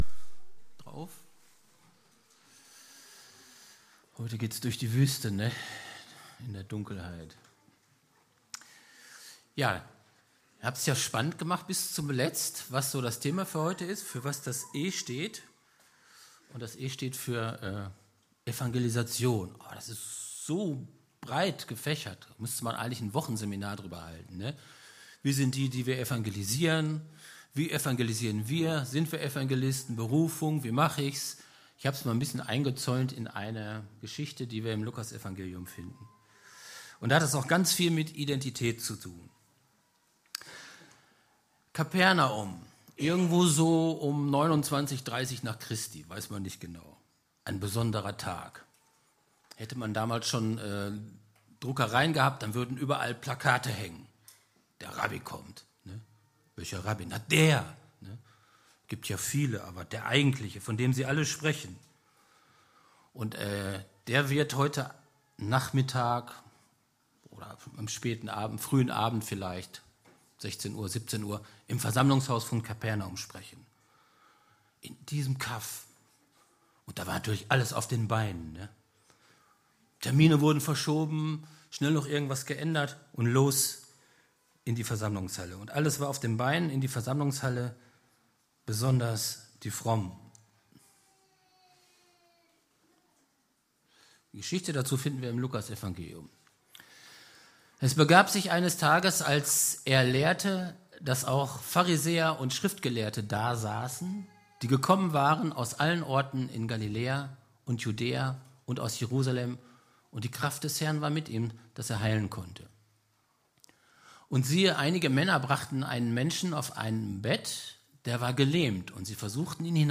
Predigt vom 17. März 2019 – efg Lage
Gottesdienst